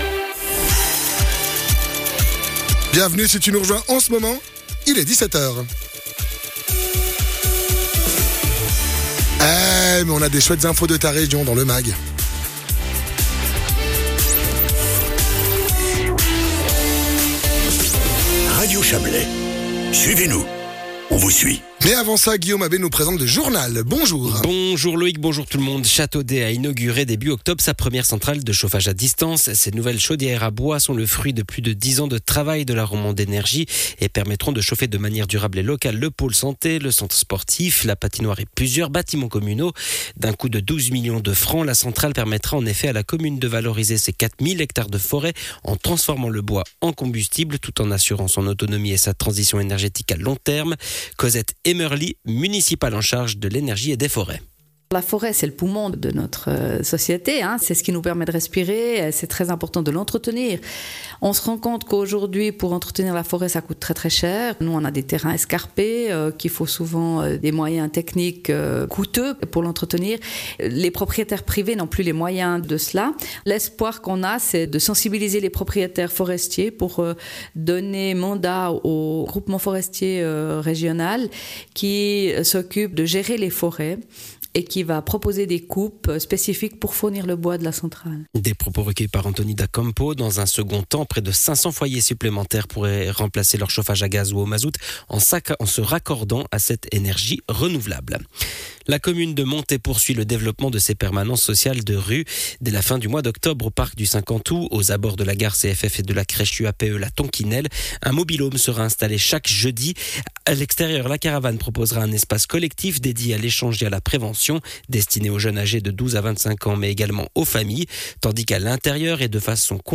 Les infos Radio Chablais en replay